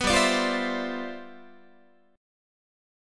Listen to G13 strummed